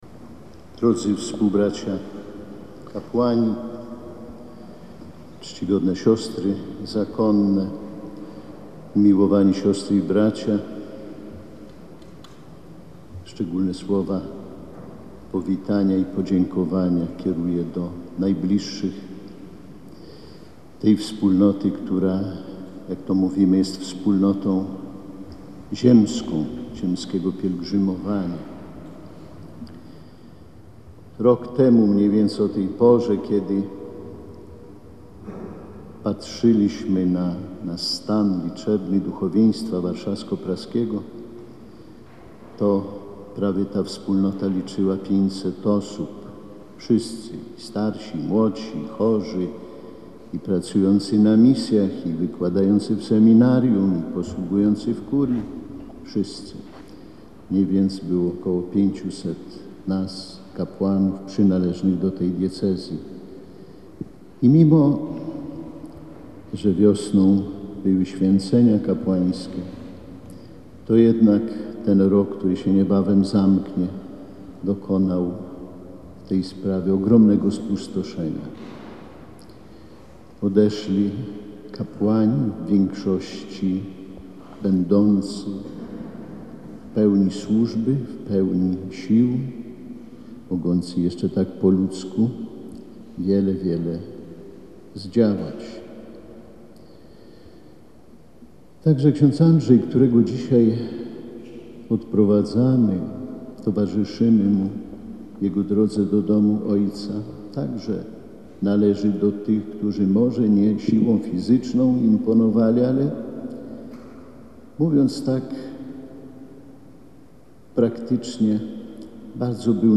Homilię wygłosił ordynariusz diecezji warszawsko-praskiej bp Romuald Kamiński.
bp-kaminski-cala-homilia.mp3